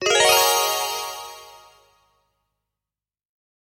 Звук волшебного пополнения счета